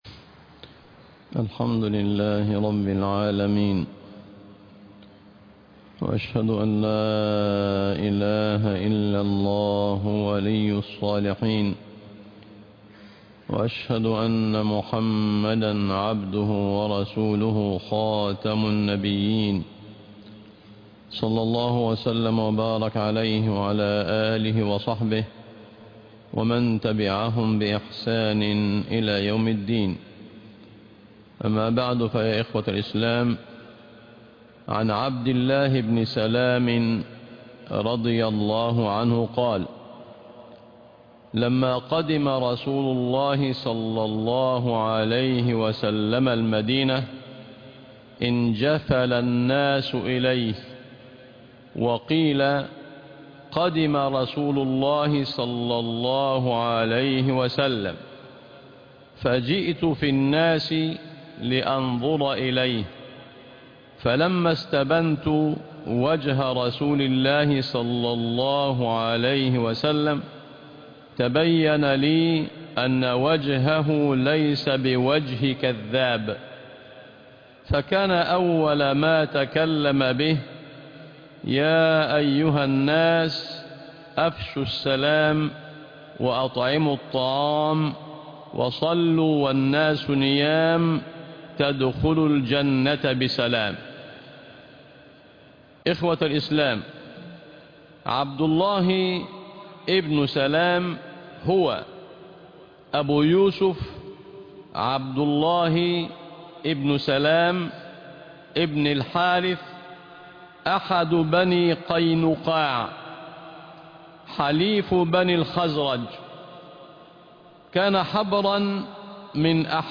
السلام رسالة الإسلام - الجمعة ٩ محرم ١٤٤٧هـ II مجمع نور التوحيد بالشين